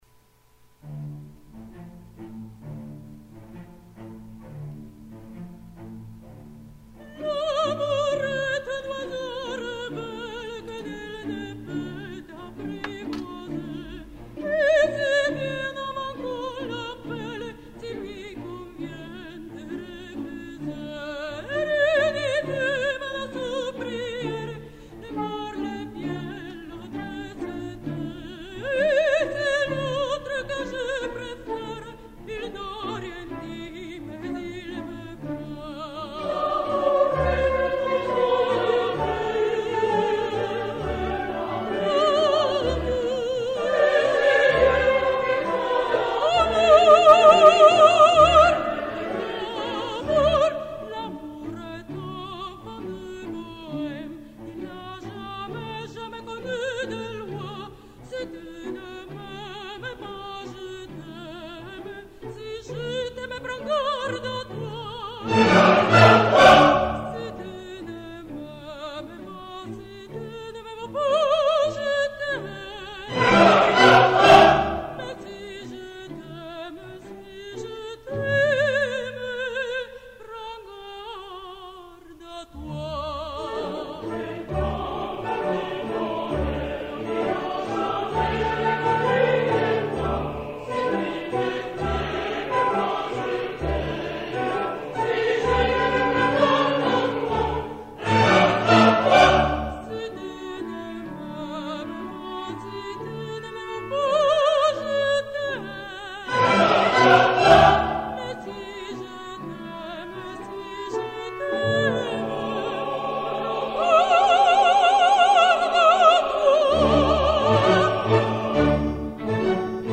Ария Кармен с хором (Carmen) - L'amour est un oiseau rebelle